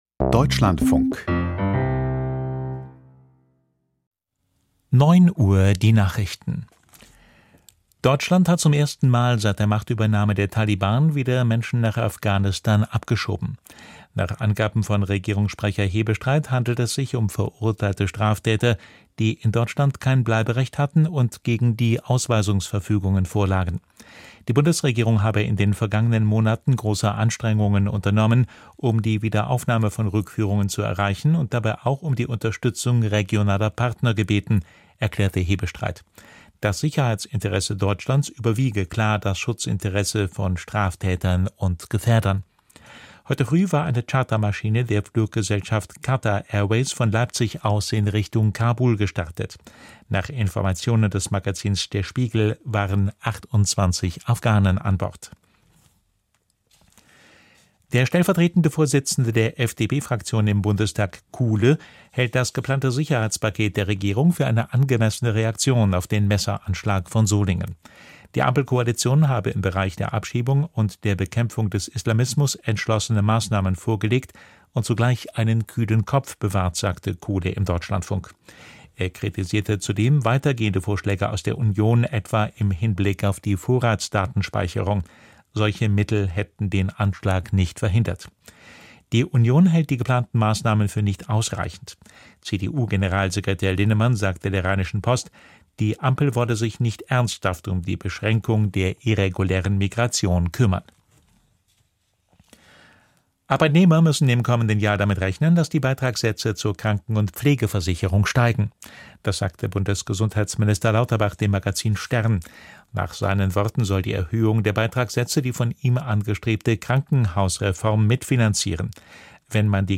Nach Solingen und vor Landtagswahlen in Sachsen und Thüringen - Wie aufgeheizter Stimmung begegnen in den evangelisch geprägten Bundesländern? - Interview mit Heinrich Bedford-Strohm, Weltkirchenrats-Vorsitzender und Ex-EKD-Ratsvorsitzender - 30.08.2024